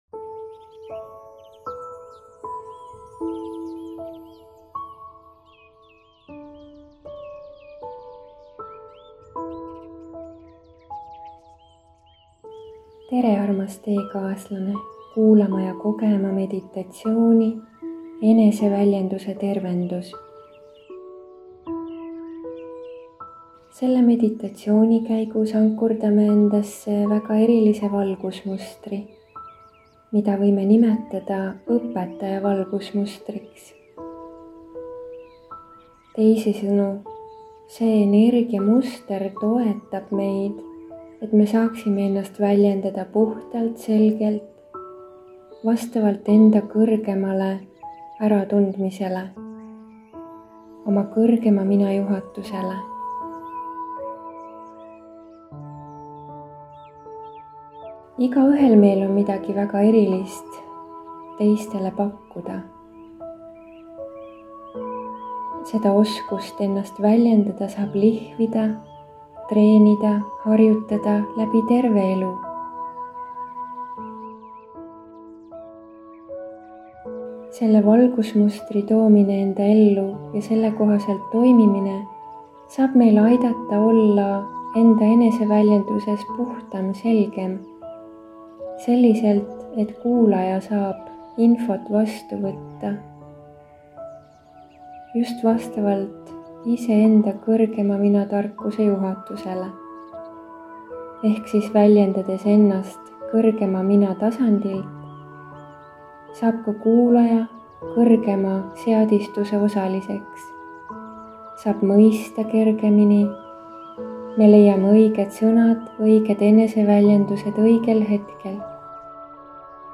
Enda eest hoolitsemise oskuse suurendamise meditatsioon